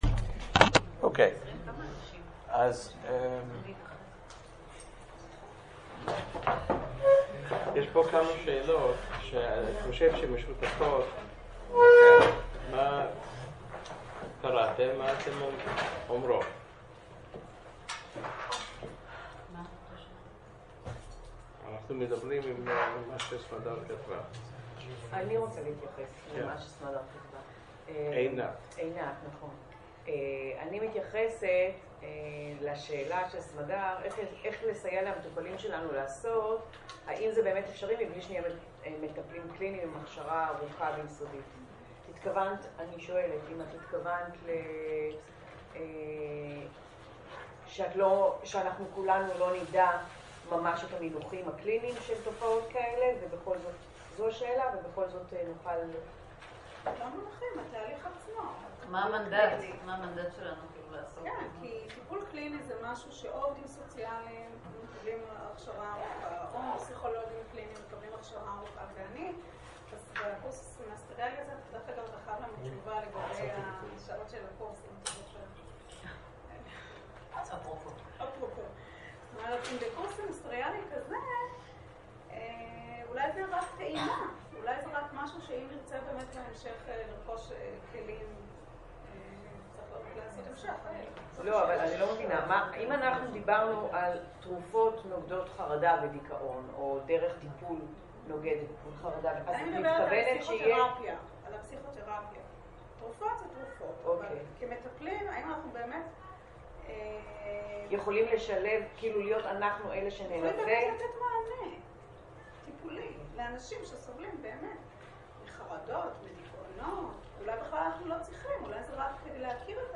שיעור #2 חלק א שיעור #2 חלק ב